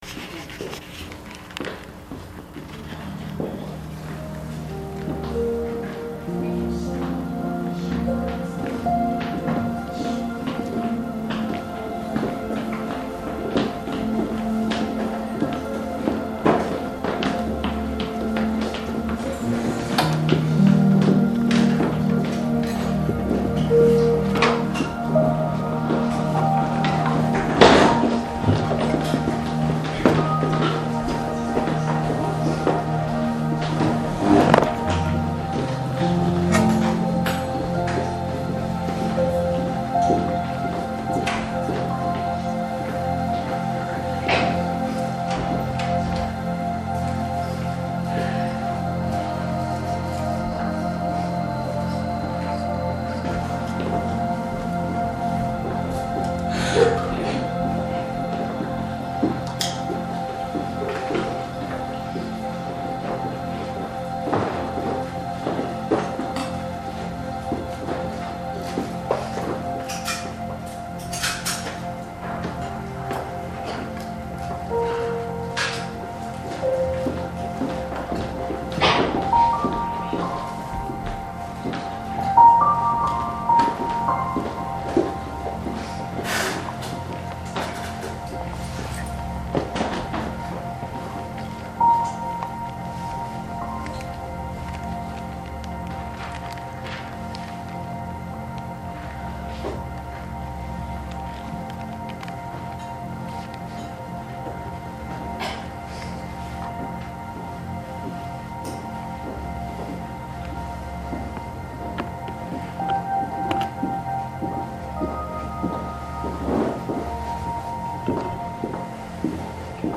主日恩膏聚会录音